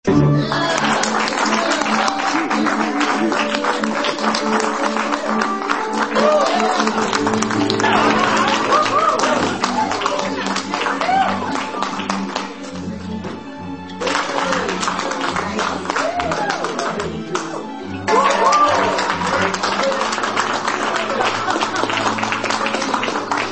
Applause Birdemic Style